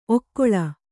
♪ okkoḷa